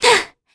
Talisha-Vox_Attack1_kr.wav